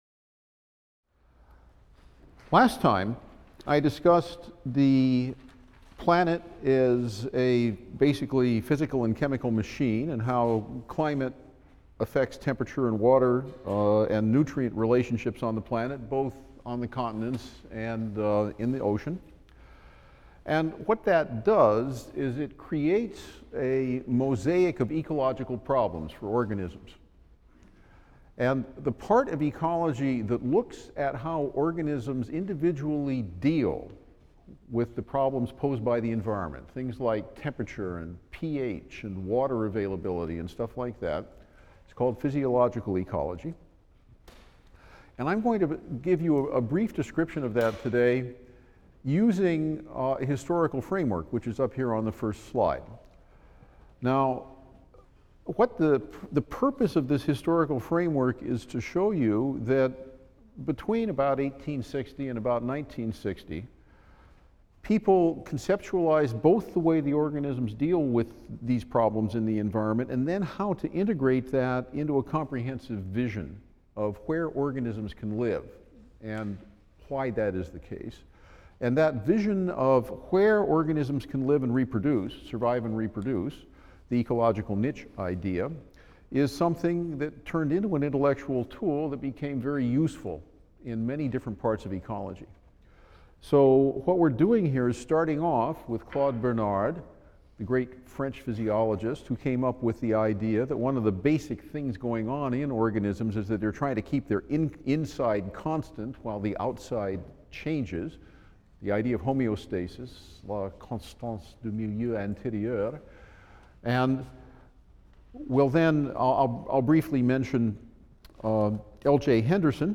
E&EB 122 - Lecture 25 - Interactions with the Physical Environment | Open Yale Courses